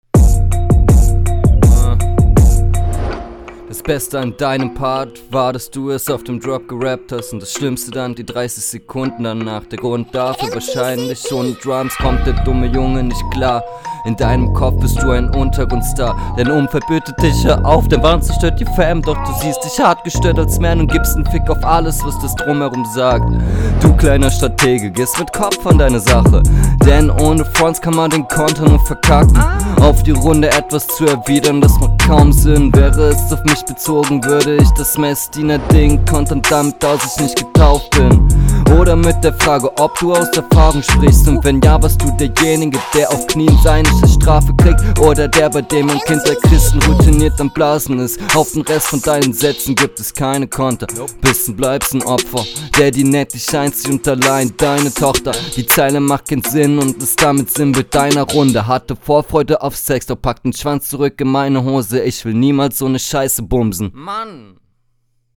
Hört sich hier so an als ob du nur eine einzige Spur aufnimmst.
deutlich unsicherer aufm Beat, Reime teilweise schlecht platziert.